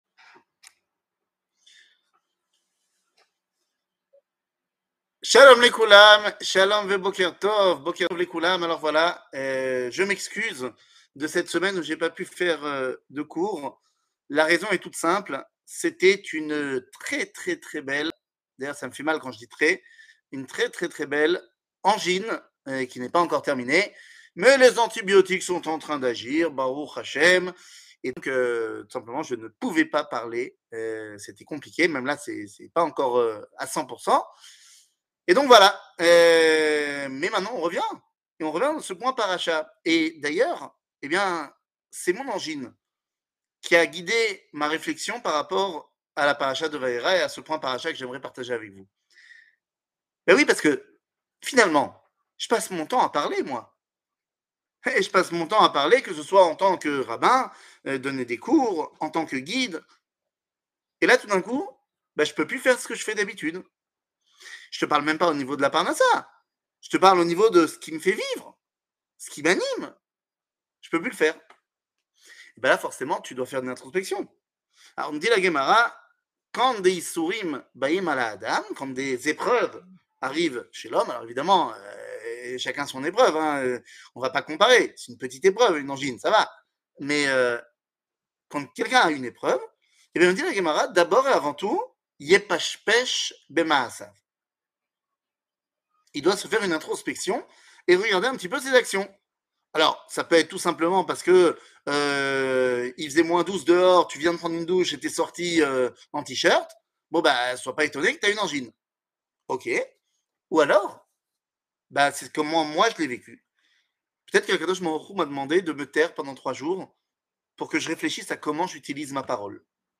שיעור מ 10 נובמבר 2022 06MIN הורדה בקובץ אודיו MP3 (5.89 Mo) הורדה בקובץ וידאו MP4 (17.19 Mo) TAGS : שיעורים קצרים